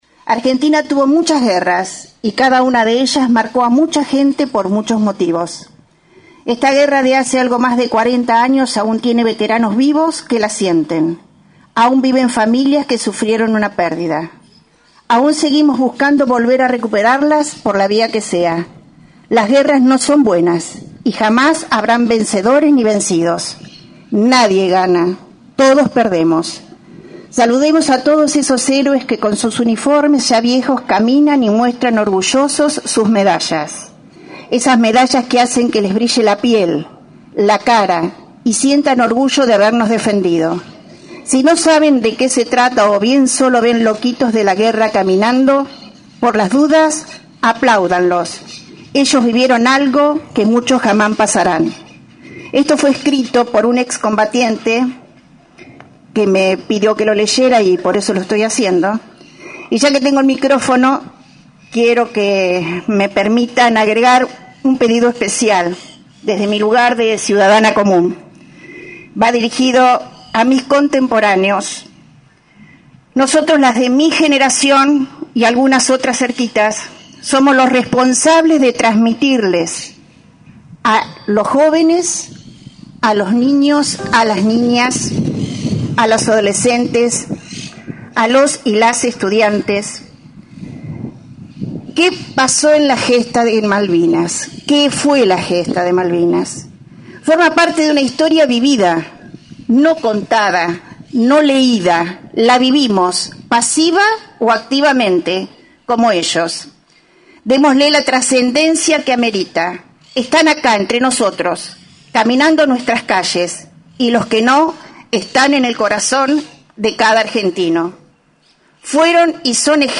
Pasada las 11 de la mañana, se llevó a cabo en el nuevo monumento ubicado en Plaza Mitre el acto en conmemoración de los 41º años del desembarco en Malvinas.